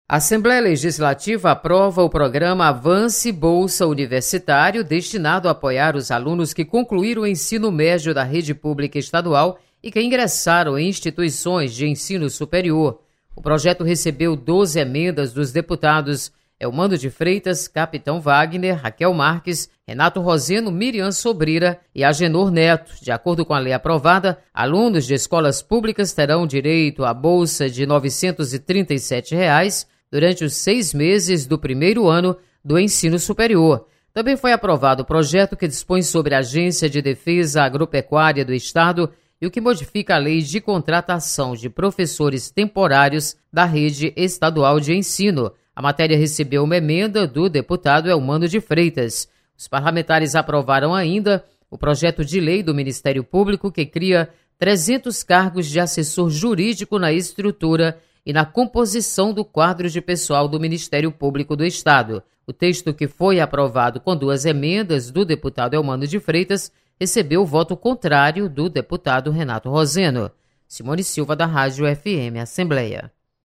Aprovado Programa Avance. Repórter